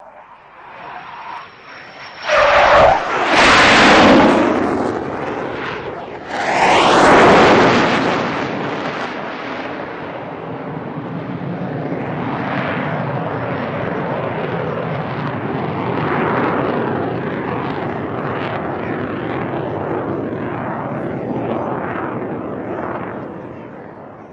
F-104 Starfighter
F-104 Lockheed Pass By, Very Fast Left to Right, Hot